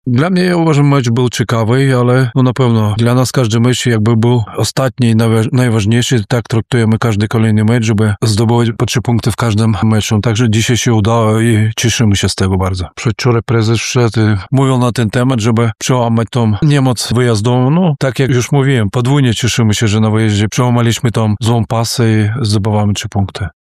Komentował na pomeczowej konferencji prasowej